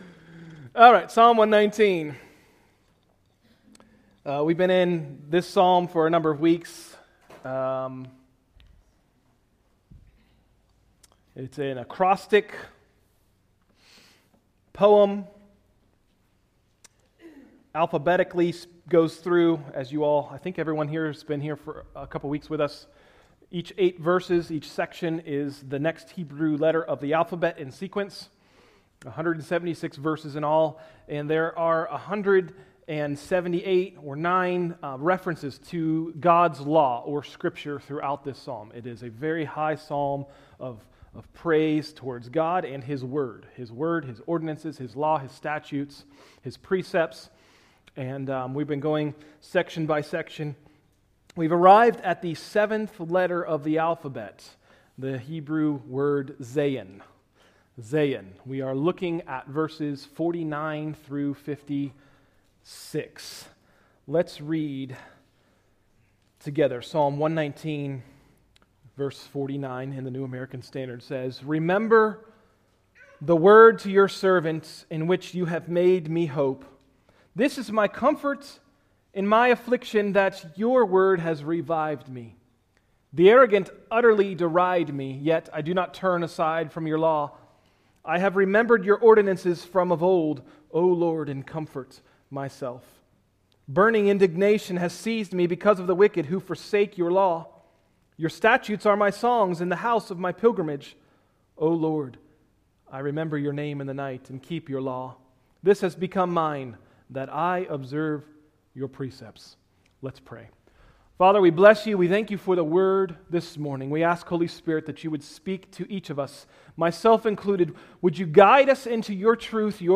Sermons – Tried Stone Christian Center